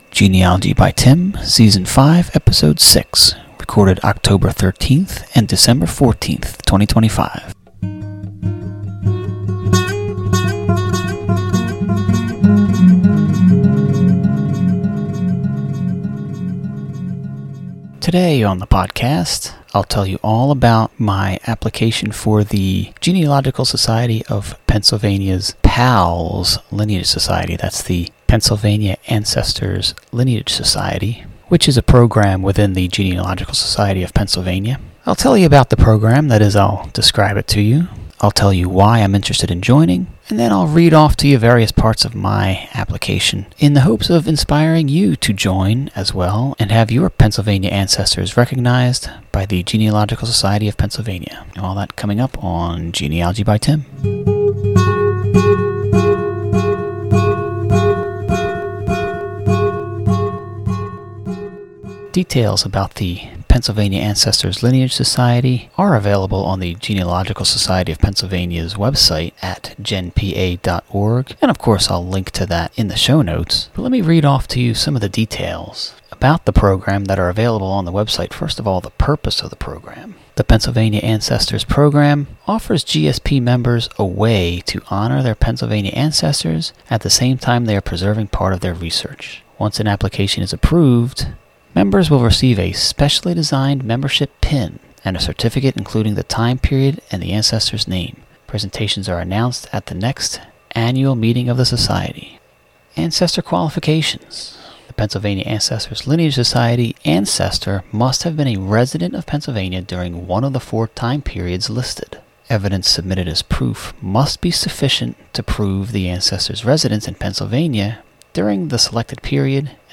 This month on the podcast I discuss the Genealogical Society of Pennsylvania's PALS program. I describe what it is and why I'm interested in joining. I read the Society's privacy policy from the FAQ section of its web site.
There is a lot of reading and a lot of rambling, but there is a lot of good information here that I've been waiting a long time to get down on audio.